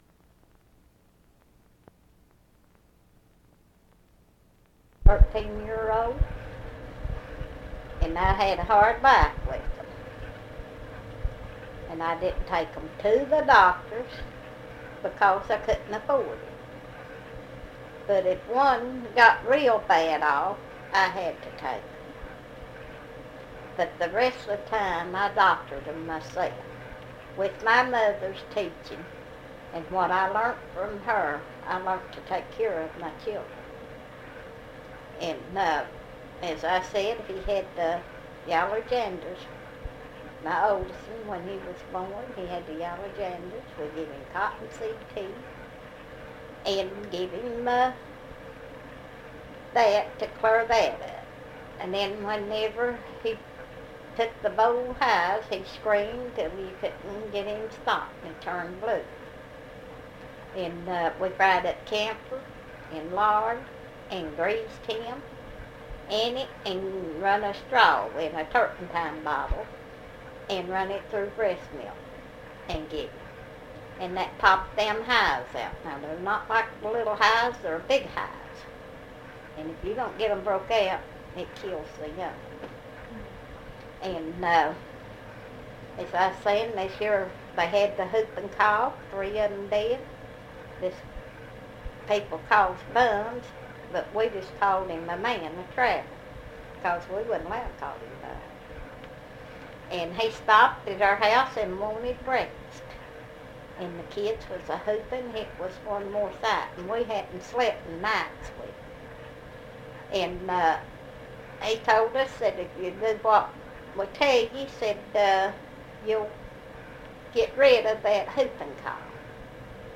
Patterns in Parenting Oral History Project